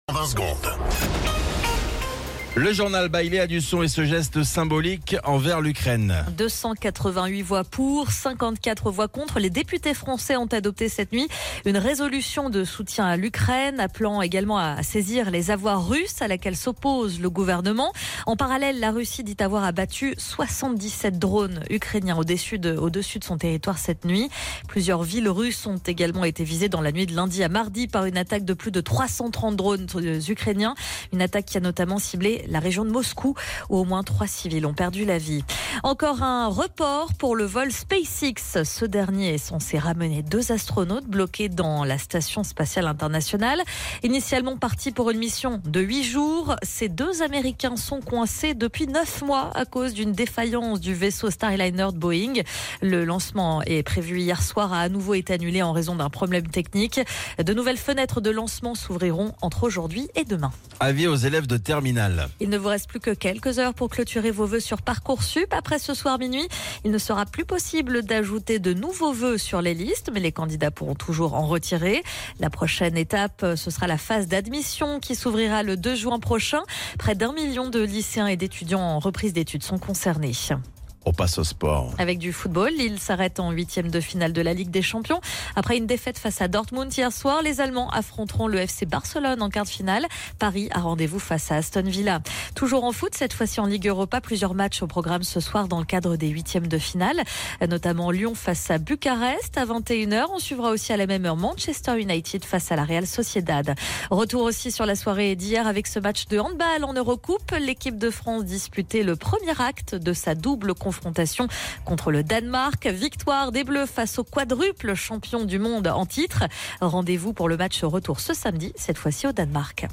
Flash Info National 13 Mars 2025 Du 13/03/2025 à 07h10 .